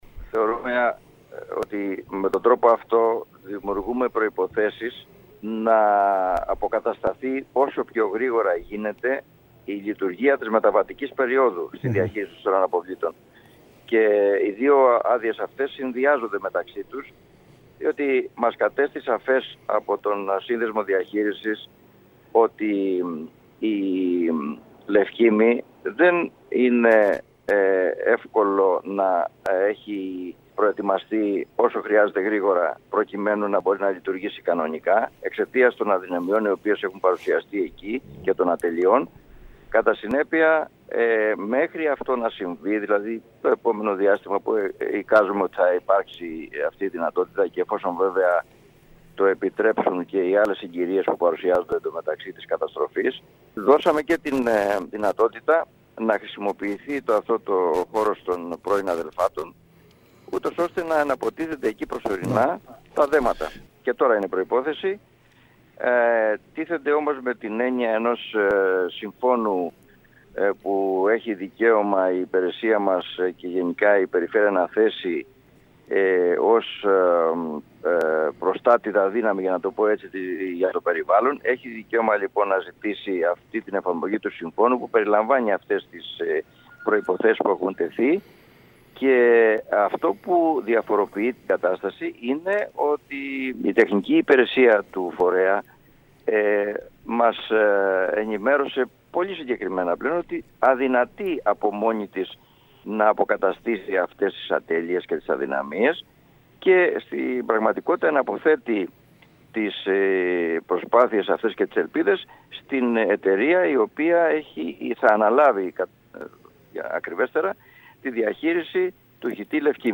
Τους λόγους για τους οποίους υπέγραψε την υπό όρους αδειοδότηση του ΧΥΤΥ Λευκίμμης και τη χρήση του οικοπέδου των αδελφάτων στο Τεμπλόνι, ανέφερε ο Περιφερειάρχης Ιονίων Νήσων Θόδωρος Γαλιατσάτος μιλώντας στην ΕΡΤ Κέρκυρας. Ο κ. Γαλιατσάτος τόνισε ότι ο ΣΥΔΙΣΑ κατέστησε σαφές ότι τεχνικά, δεν μπορεί να υλοποιήσει το σύνολο των προϋποθέσεων και ότι εναποθέτει αυτή τη δυνατότητα στην εταιρεία που θα αναλάβει τη λειτουργία του ΧΥΤΥ Νότιας Κέρκυρας.